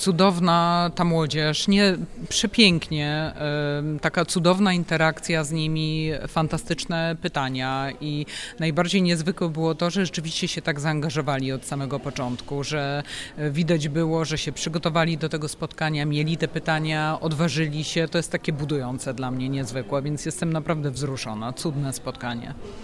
We wtorkowy poranek (20 maja) odbyło się kolejne spotkanie z cyklu „Śniadanie Mistrzów PWT”.